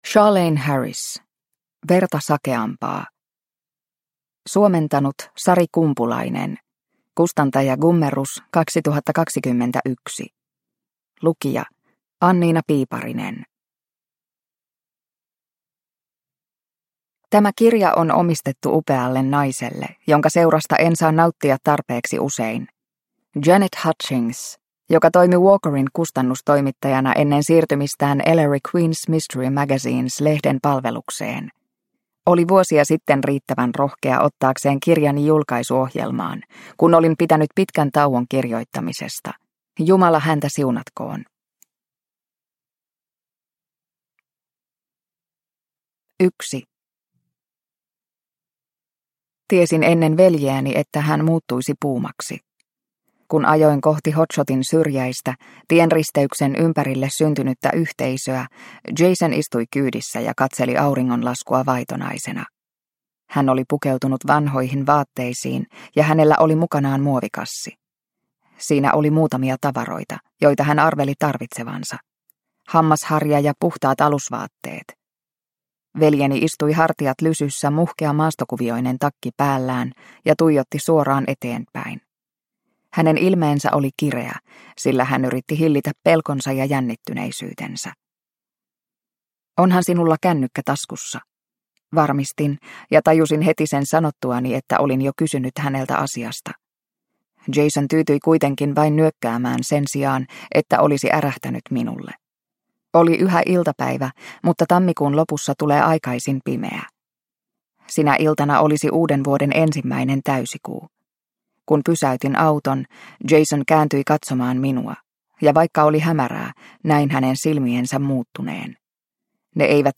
Verta sakeampaa – Ljudbok – Laddas ner